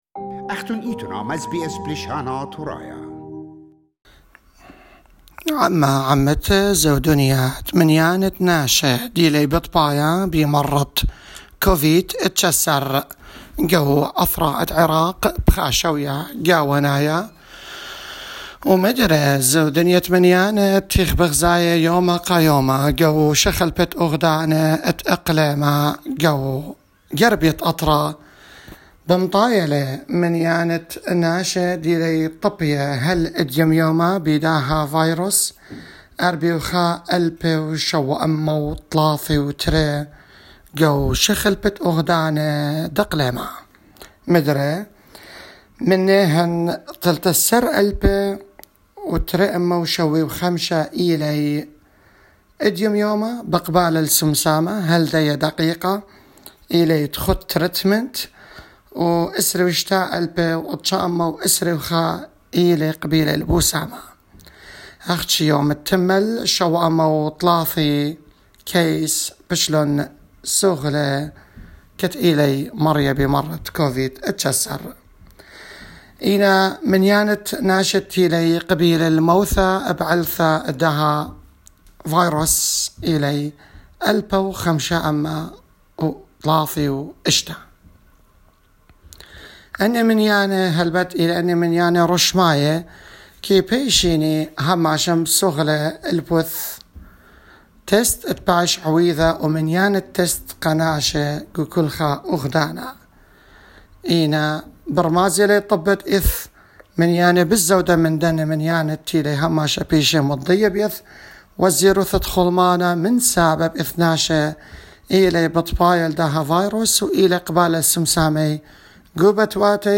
Report from the northern of Iraq